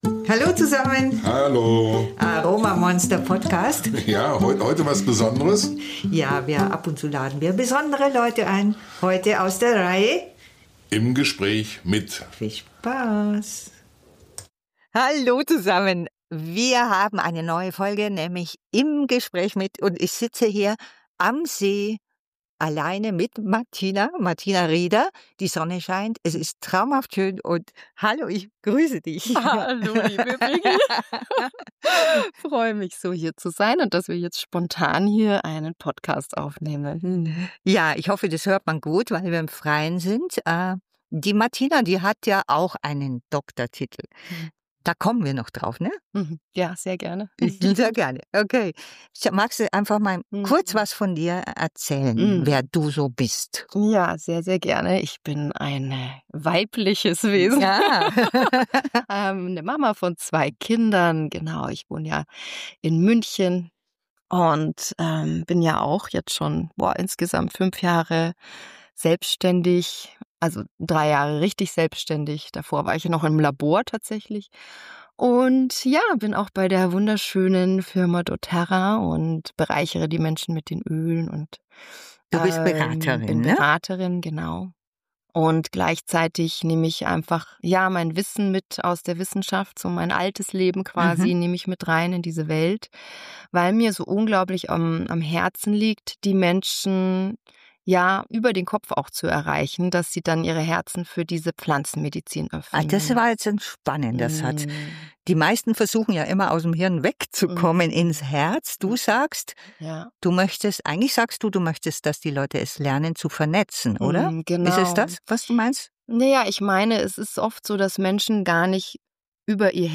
doTERRA-Leader im Gespräch